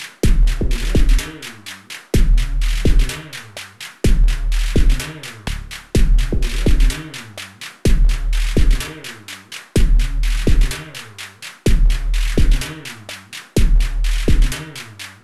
• drum sequence analog broken beat Cm - 126.wav
Recorded internal, using a Volca Drum, modulated and a Komplete Audio 6 interface.
drum_sequence_analog_broken_beat_Cm_-_126_9uK.wav